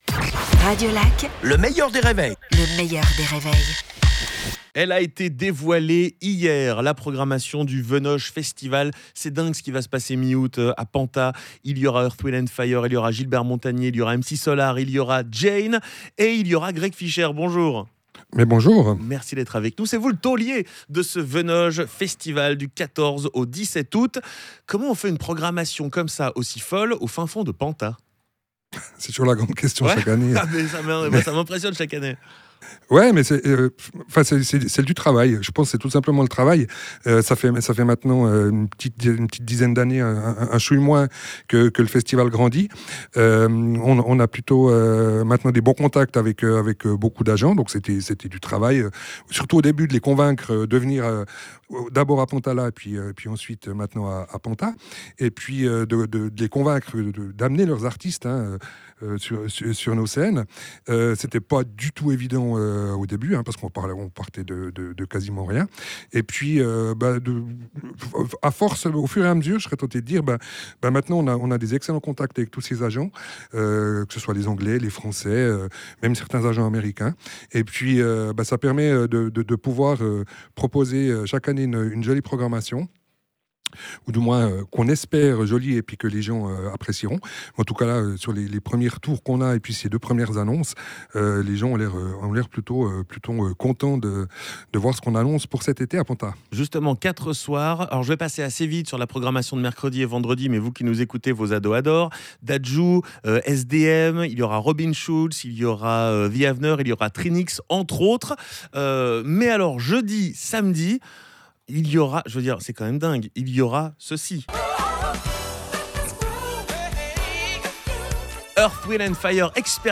Jain, MC Solaar, Imagination, Gossip et d'autres à Penthaz cet été (Interview)